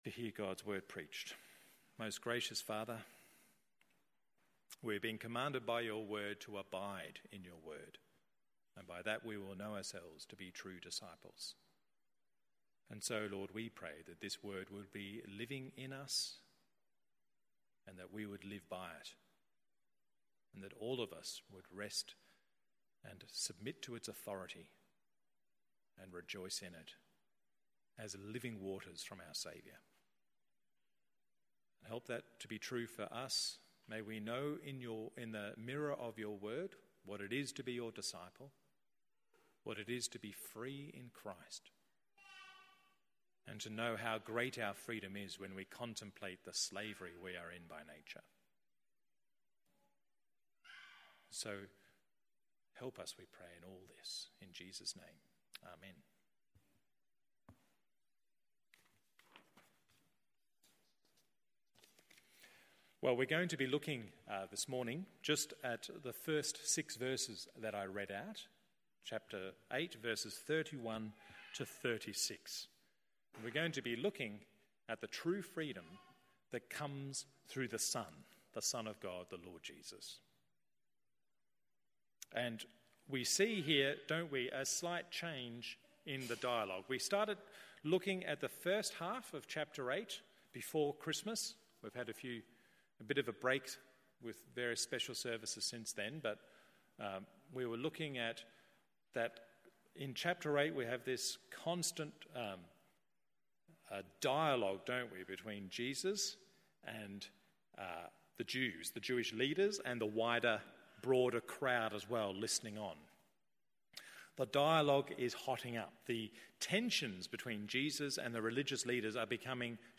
MORNING SERVICE John 8:31-59…